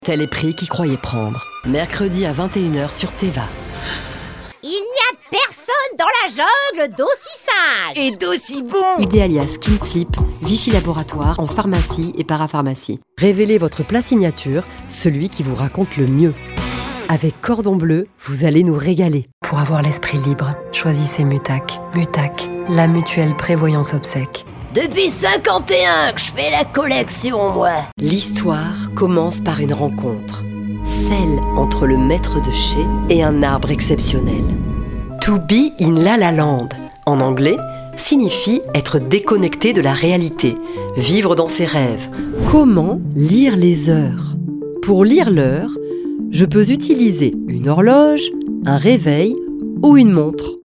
Ici on entend ma voix : TV / ciné / web / podcasts / documentaires / billboards / jingles / cirque actuel / audioguides
Enregistrés dans les studios (Paris, Marseille):